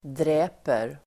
Uttal: [dr'ä:per]